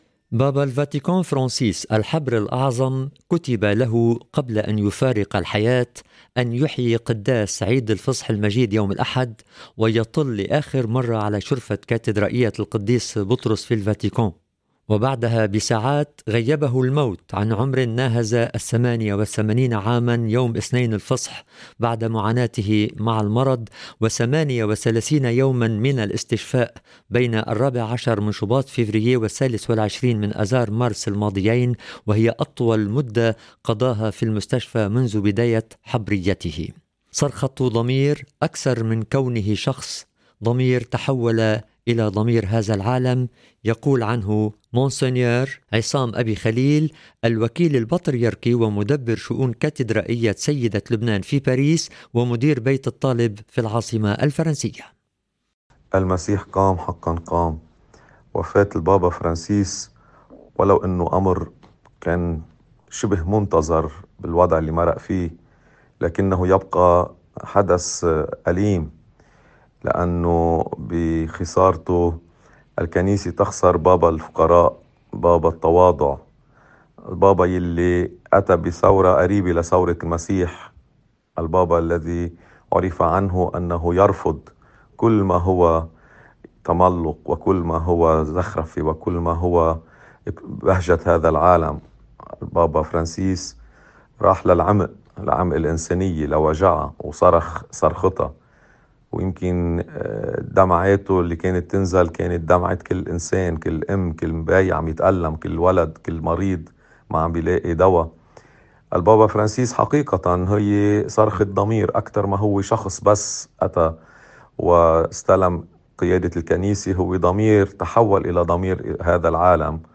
عن البابا فرانسيس ودوره والإرث الذي ترك كان لإذاعة الشرق هذا الللقاء